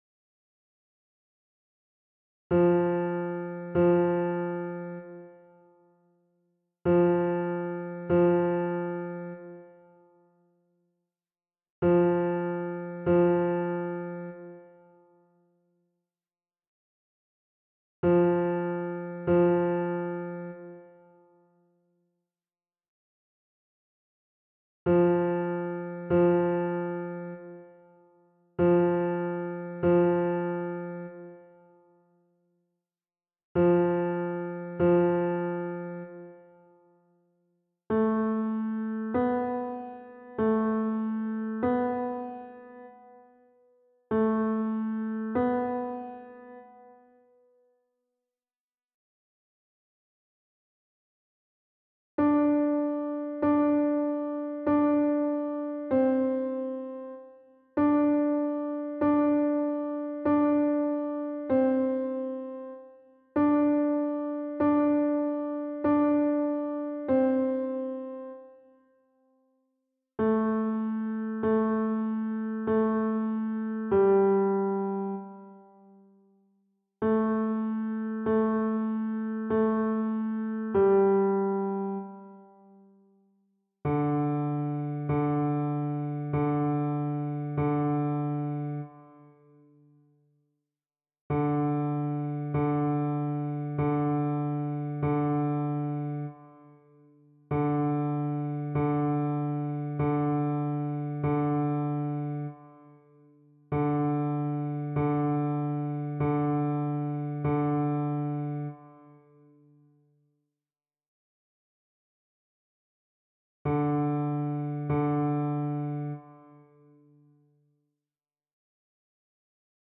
3′ score for voices